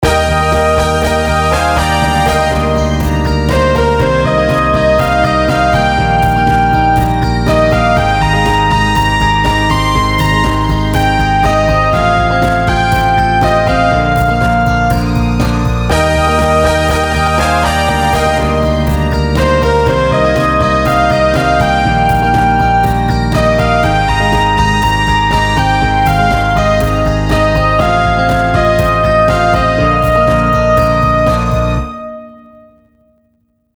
といってみても、Ｂメロだかサビだかわからないパートしか作れてないが。